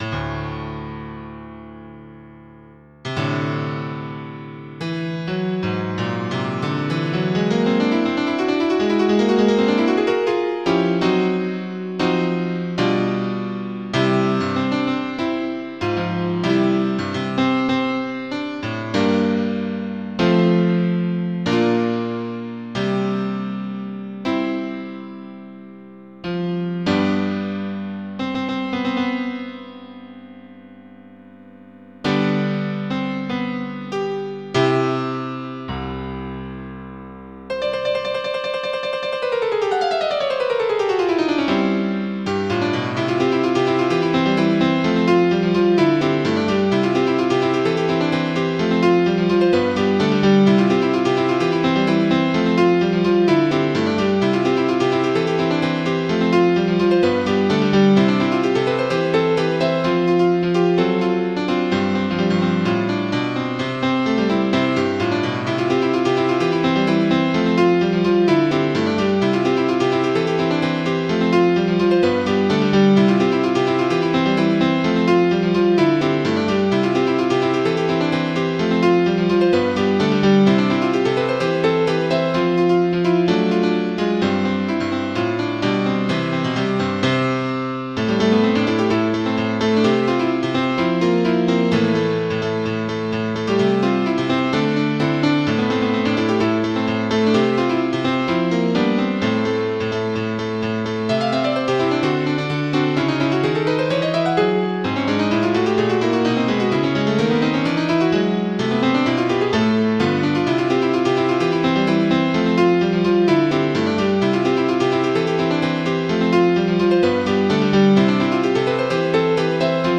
MIDI Music File
Title WinJammer Demo Type General MIDI